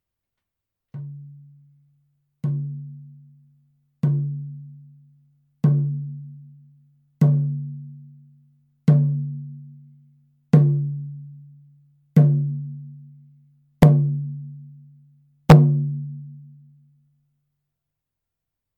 フレームドラム　ネイティブアメリカン（インディアン）スタイル
素材：牛革・天然木
パキスタン製フレームドラム 音